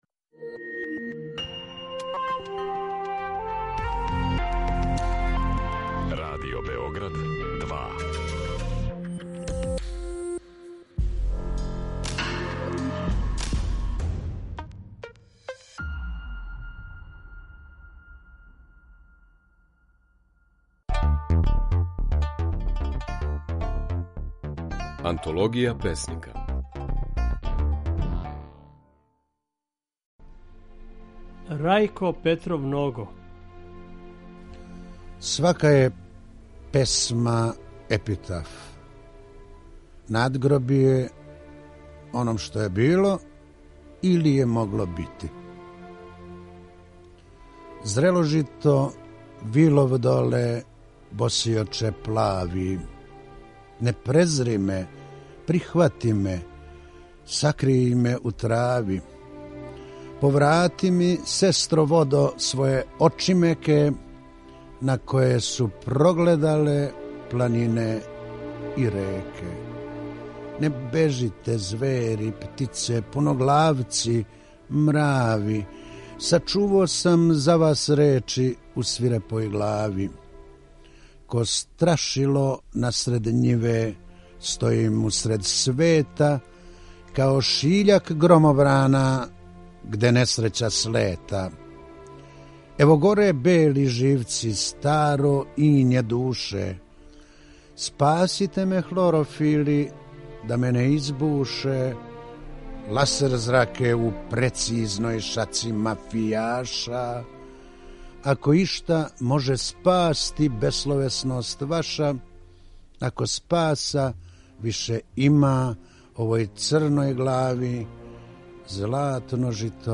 Слушаћете како своје стихове говори Рајко Петров Ного.
Емитујемо снимке на којима своје стихове говоре наши познати песници